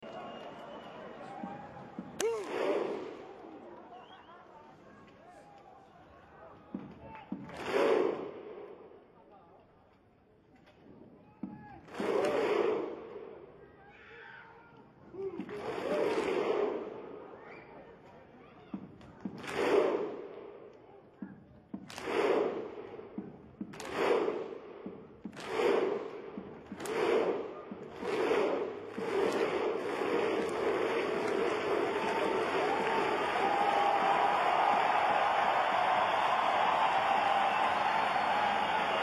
Download “Iceland Viking Thunder Clap”
Iceland-Viking-Thunder-Clap.mp3